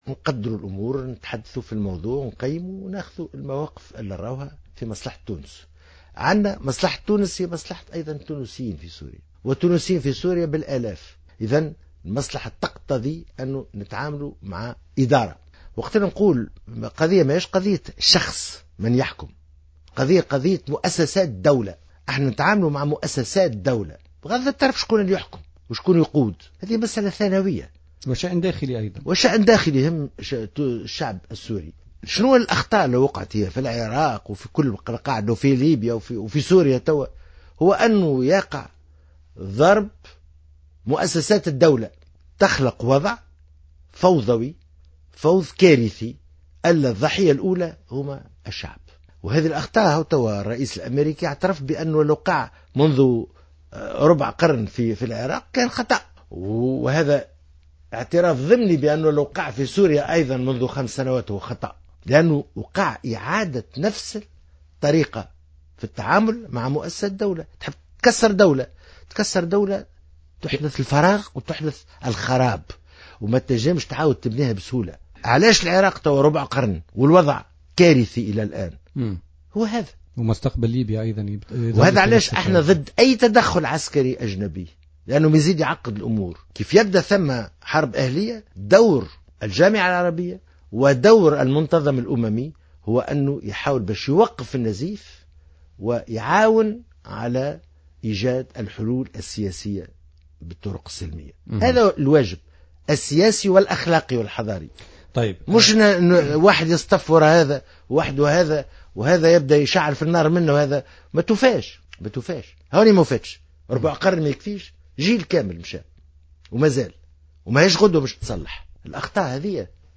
أكد وزير الخارجية الطيب البكوش ضيف بوليتيكا اليوم الإثنين 7 سبتمبر 2015 أن القرار...